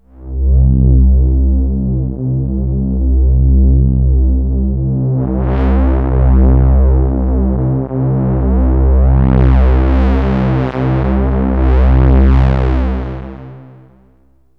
AMBIENT ATMOSPHERES-4 0001.wav